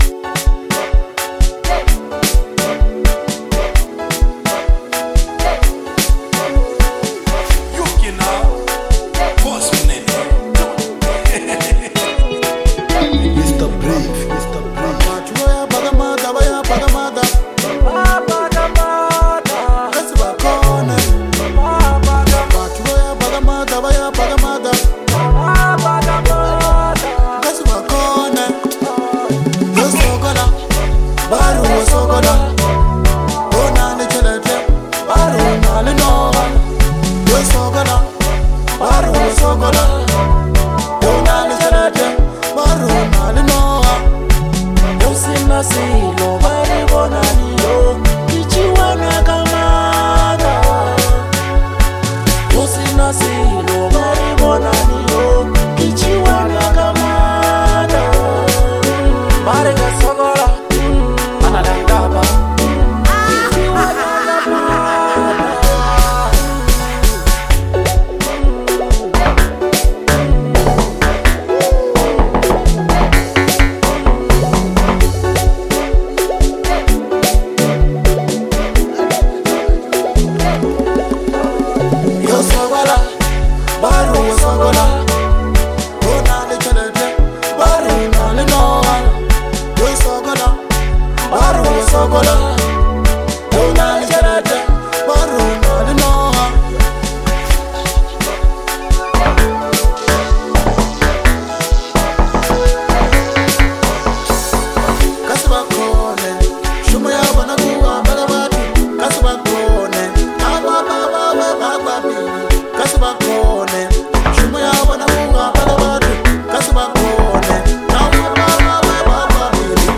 this Lekompo track is more than just a song
rhythmic production and relatable lyrics
With honest storytelling and a hard-hitting beat